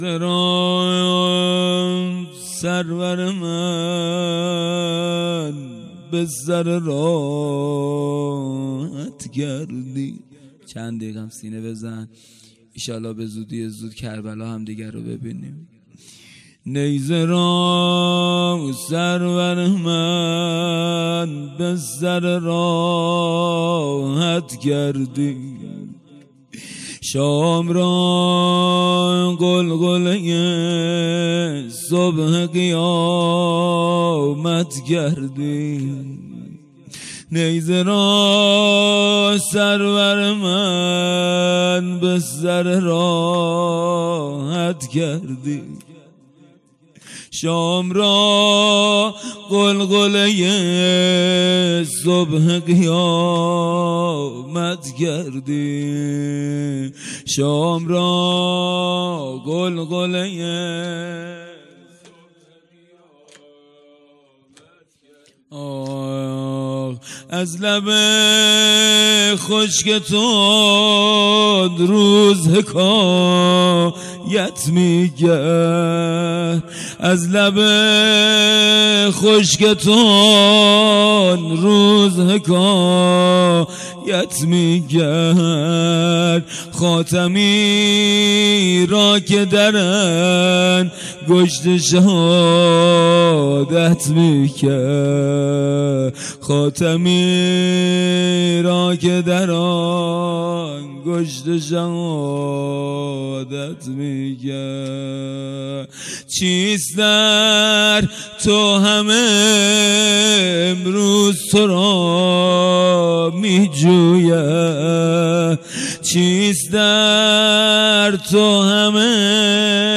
خیمه گاه - هیئت ذبیح العطشان کرمانشاه - جلسه هفتگی-زمینه و شور 971120